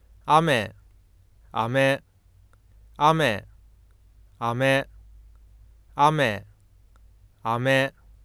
さて，このサンプルファイルは頭高型の「雨」と平板型の「飴」を発音したものです。
このとき，下のパネルの右側の軸上に表示される「160.5 Hz」という値が，カーソル位置におけるf0の値を示しています。